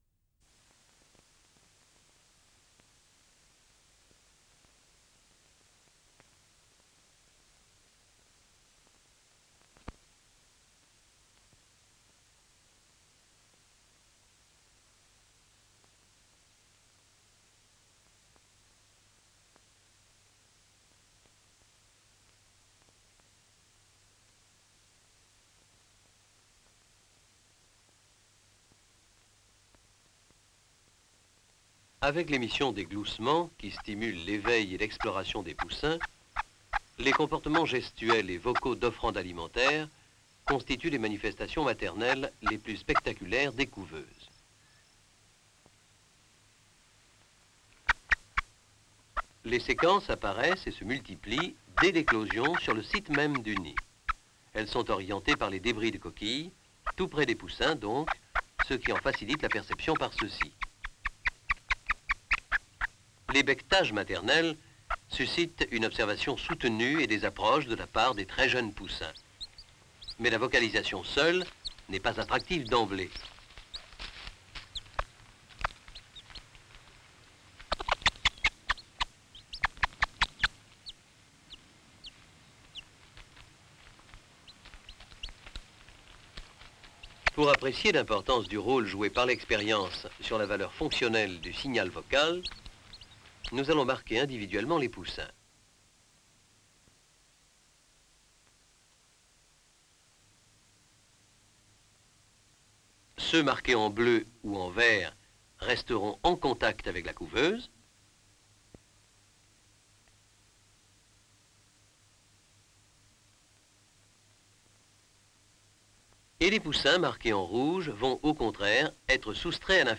Les poules manifestent, sur le nid aussitôt après l'éclosion, des activités gestuelles d'offrande alimentaire accompagnées de séquences de cris spécifiques particuliers. Le film montre comment l'expérience individuelle des poussins donne sa valeur fonctionnelle attractive au signal sonore de la mère.